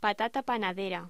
Locución: Patata panadera